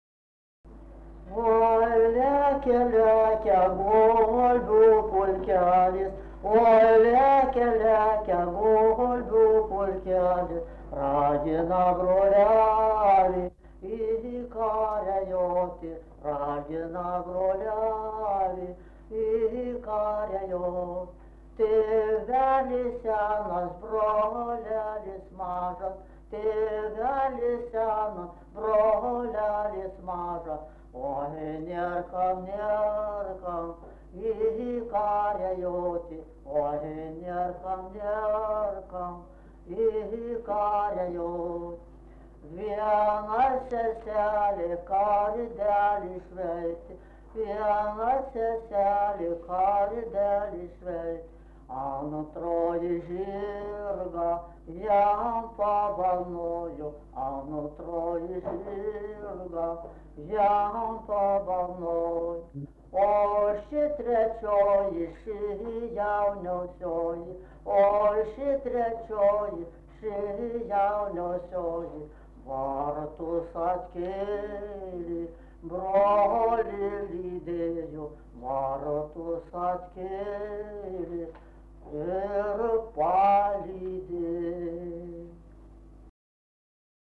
daina, karinė-istorinė